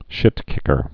(shĭtkĭkər)